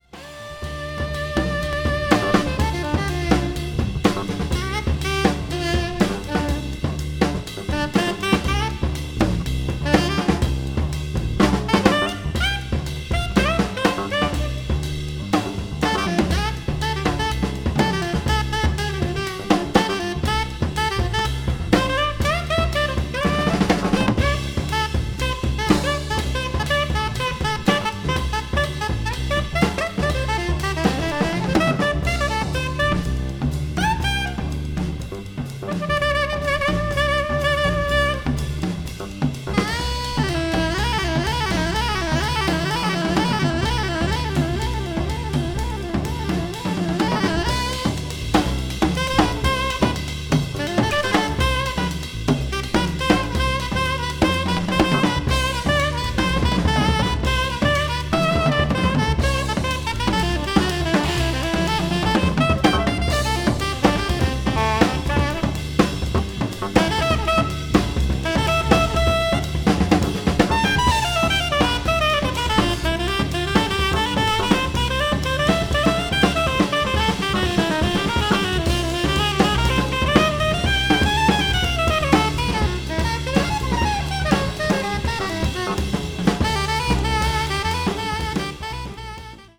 media : EX-/EX(わずかにチリノイズが入る箇所あり,A2:軽いプチノイズ1回あり)
contemporary jazz   ethnic jazz   post bop   spritual jazz